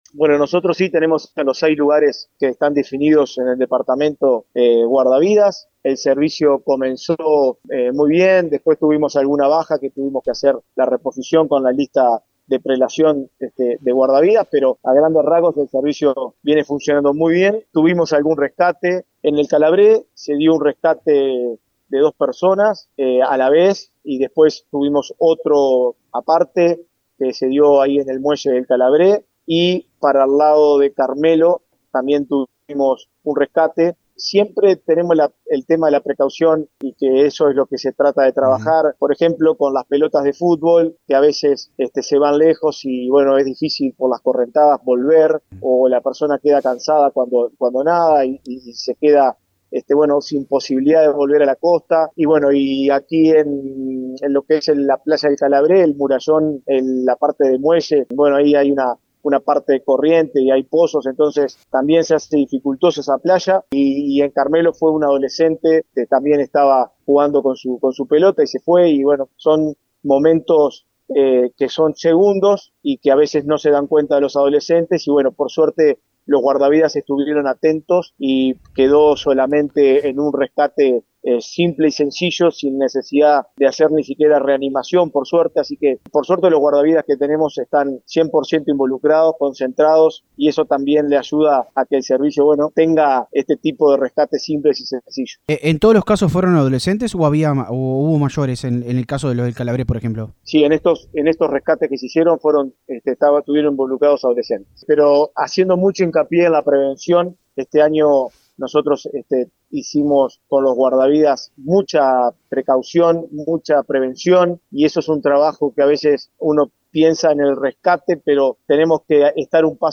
Diego Berreta, director de Deportes de la Intendencia de Colonia, explicó que las condiciones en El Calabrés son complejas debido a la presencia de pozos y correntadas cerca del murallón, lo que dificulta el regreso a la costa.
A continuación, escuchamos a Berreta detallar estas actuaciones y el balance del servicio.